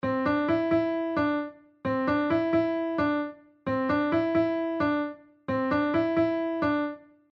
いなりですが、DAWでピアノの音を用意して、
• Cメジャースケール（CDEFGAB）の音だけを使う
• 短いフレーズを4回繰り返す
メロディーのサンプル1
上のサンプルは「ただ4回繰り返しただけ」で、最後まで聞いても一段落した感がないですよね。
melody1.mp3